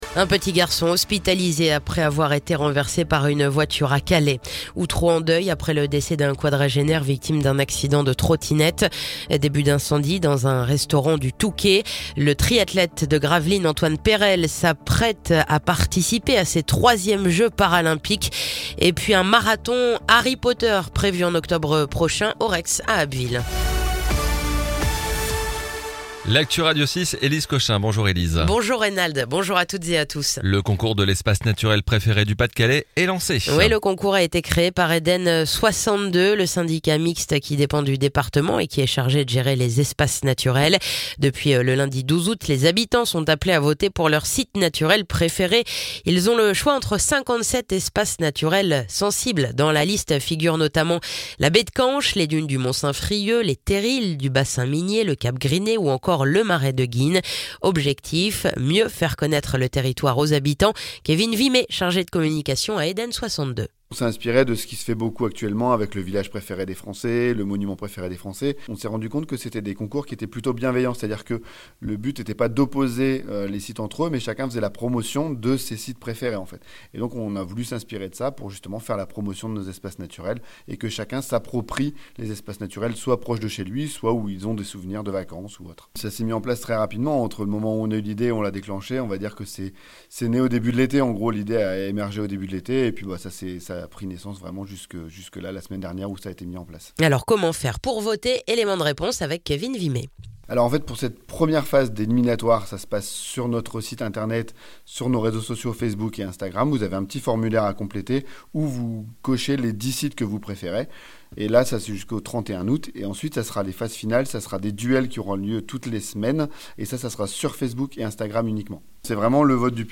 Le journal du mardi 27 août
(journal de 9h)